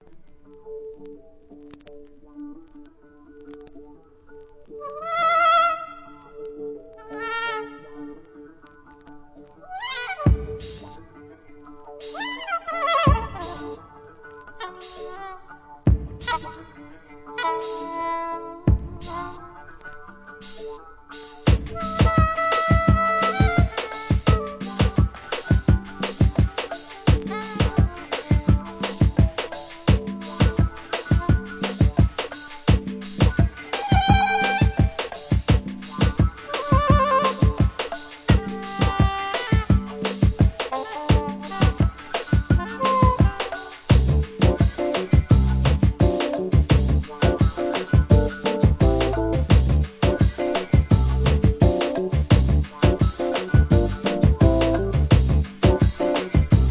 Bossa Nova, Latin Jazz